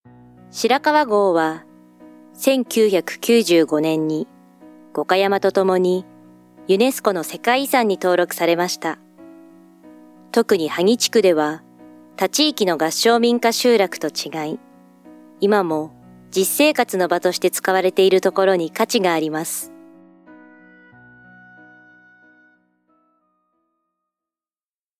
【ボイスサンプル】
micナレーション：